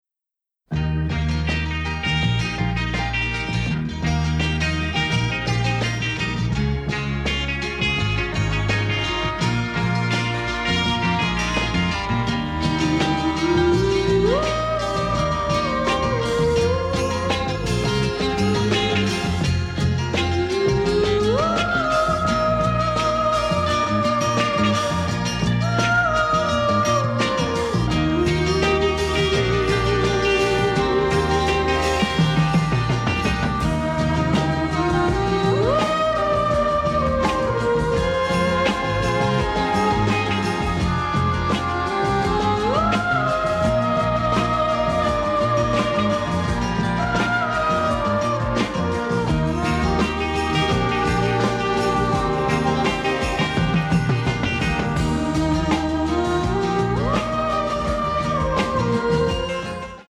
and the resulting sound quality is very much improved.